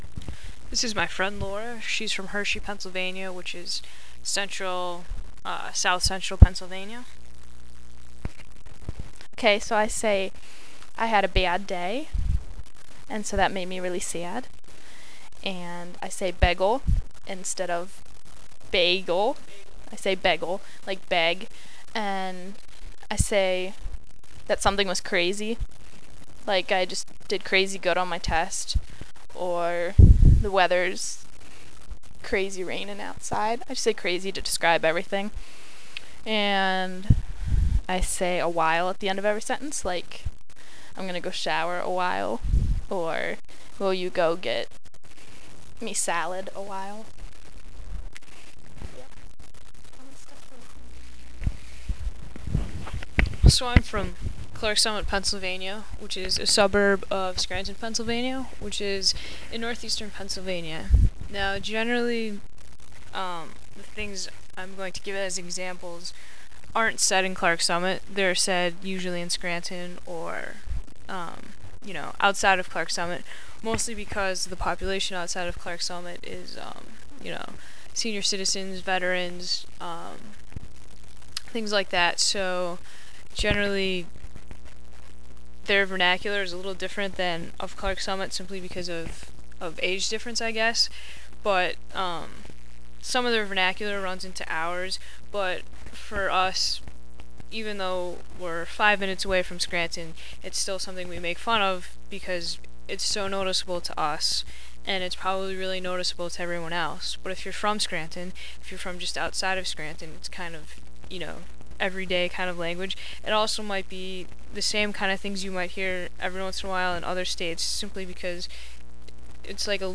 In the field data-click to listen!